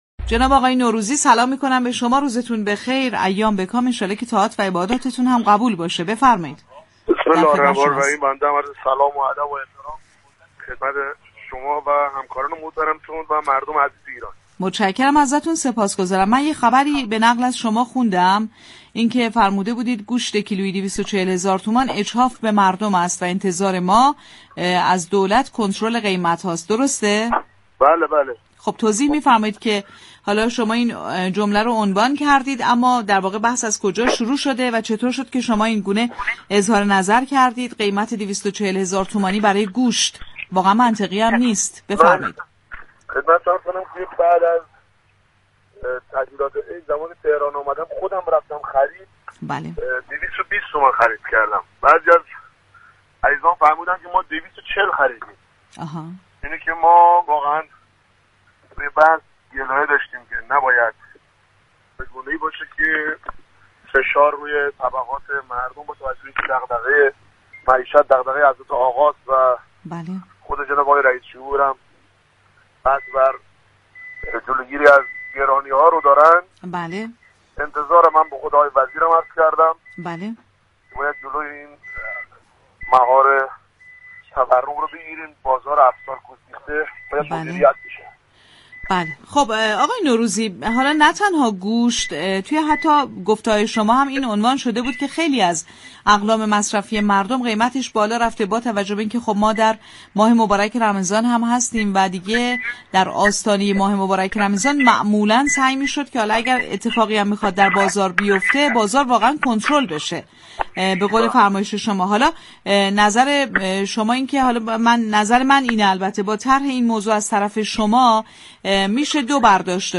به گزارش پایگاه اطلاع‌رسانی رادیو تهران، رحمت الله نوروزی عضو كمیسیون كشاورزی، آب، منابع طبیعی و محیط‌زیست مجلس در گفت‌وگو با بازار تهران درخصوص اظهاراتش در مورد گرانی گوشت، لبنیات و برنج گفت: بعد از تعطیلات نوروز هر كیلوگرم گوشت قرمز را به قیمت 220هزار تومان خریدم به همین دلیل گلایه‌ای داشتم كه نباید شرایط به‌گونه‌ای باشد كه فشار بر روی مردم زیاد باشد.